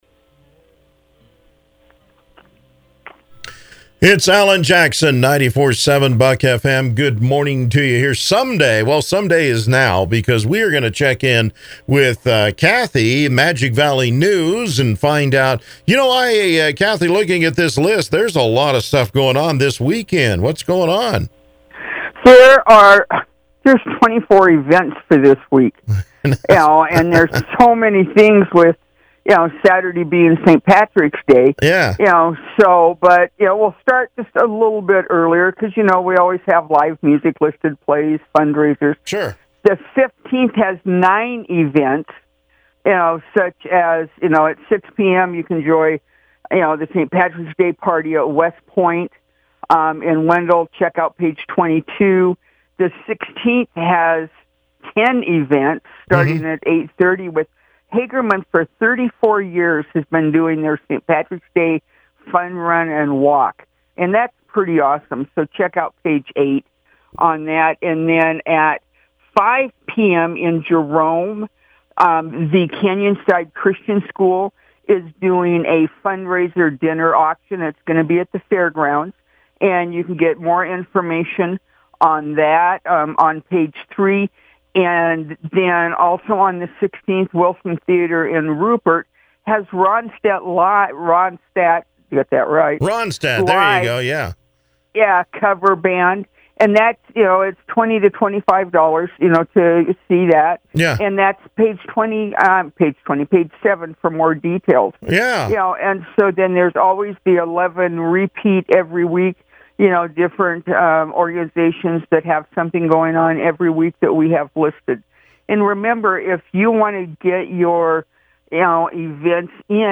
Interviews are around 7:40a every Tuesday.
Radio Chats